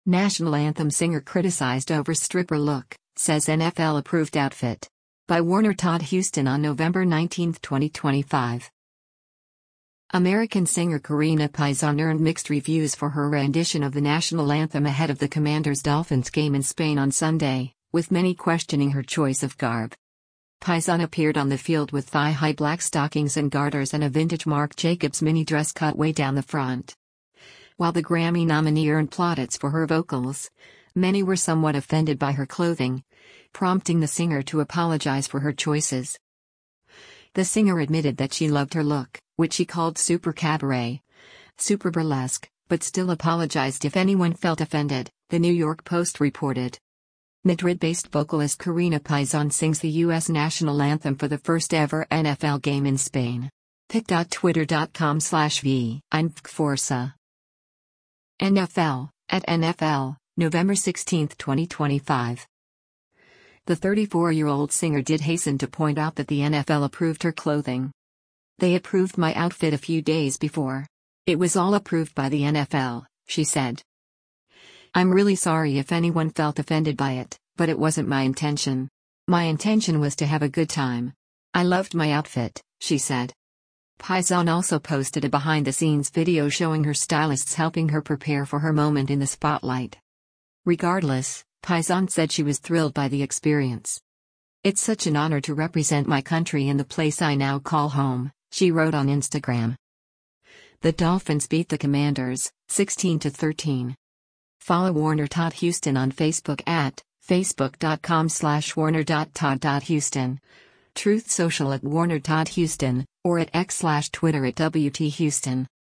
national anthem ahead of the Commanders-Dolphins game in Spain on Sunday